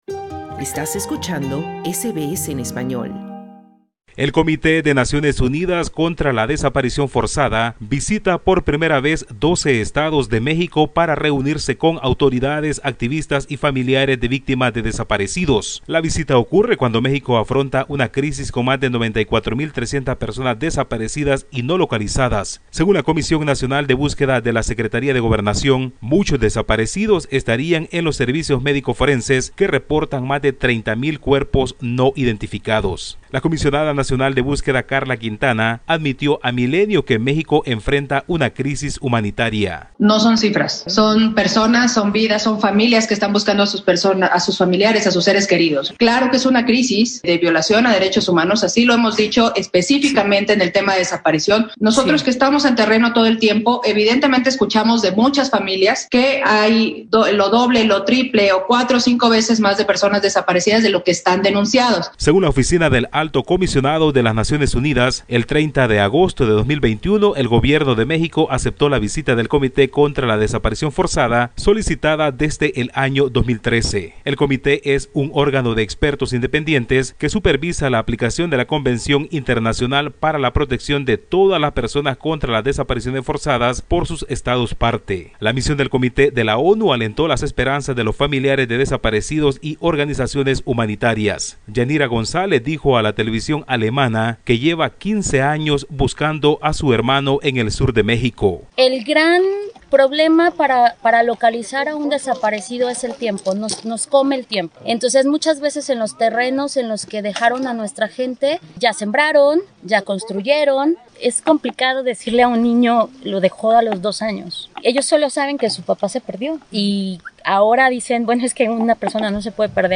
El Comité de Naciones Unidas contra la Desaparición Forzada visita por primera vez doce estados de México para reunirse con autoridades, activistas y familiares de víctimas de desaparecidos. La visita ocurre cuando México afronta una crisis con más 94 000 personas desaparecidas. Escucha el informe del corresponsal de SBS Spanish en América Latina